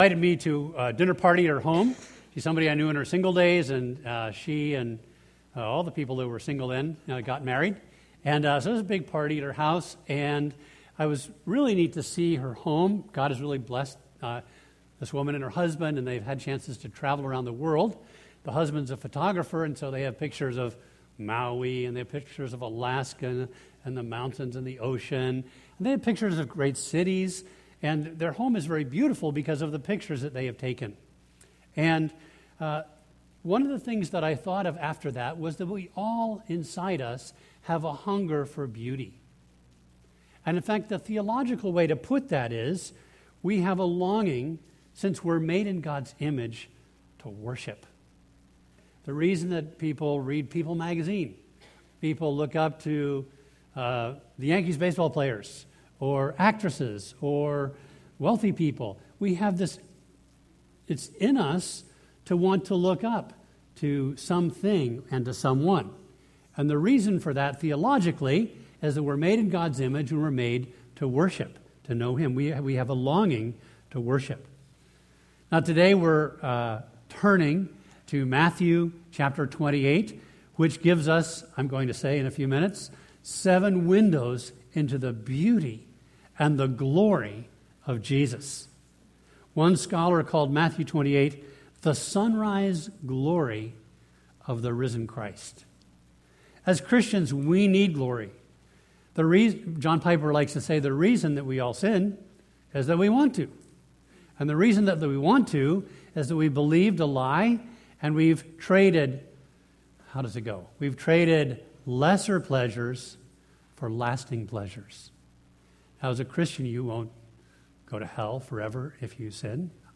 A message from the series "Easter Sermons."
From Series: "Easter Sermons"